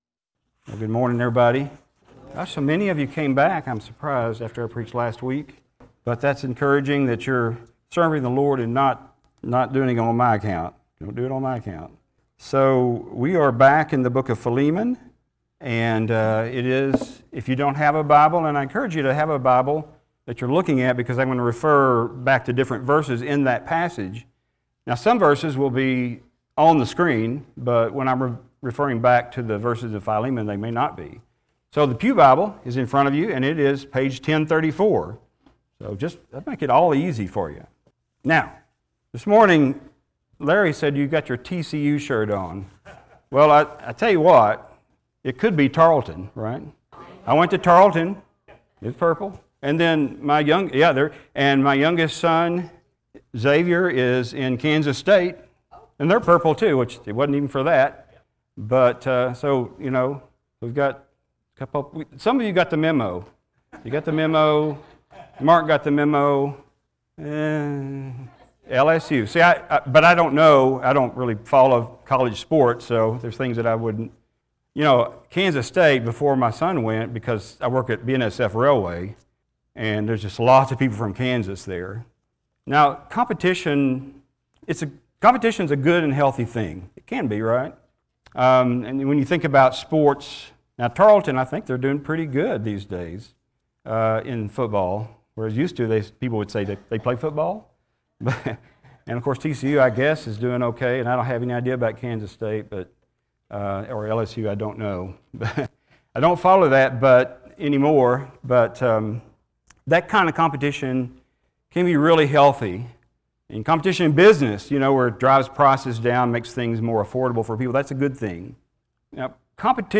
The Book of Philemon Passage: Philemon 10-25 Service Type: Sunday Morning Thank you for joining us.